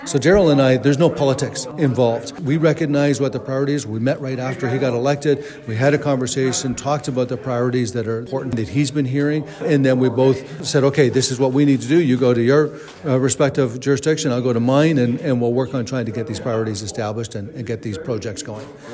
Liberal MP Mike Bossio says it’s a great example of all levels of government working together to benefit area residents, including himself and PC MPP Daryl Kramp locally.